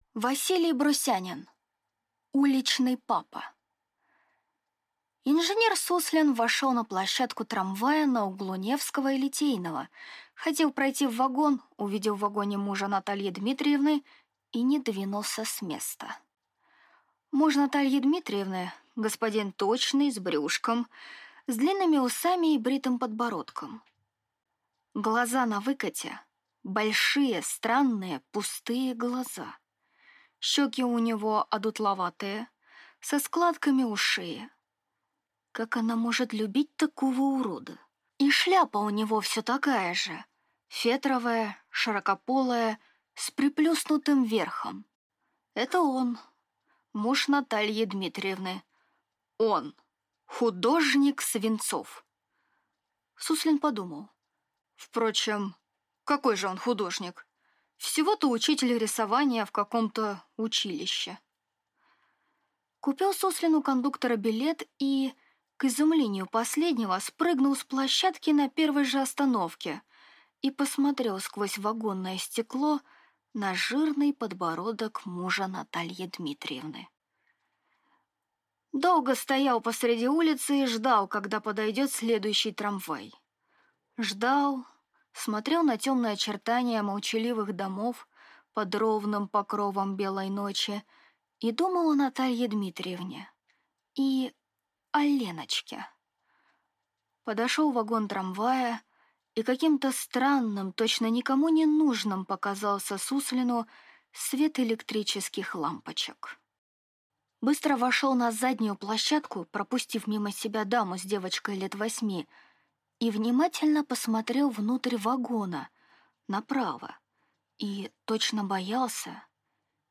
Аудиокнига Уличный папа | Библиотека аудиокниг
Прослушать и бесплатно скачать фрагмент аудиокниги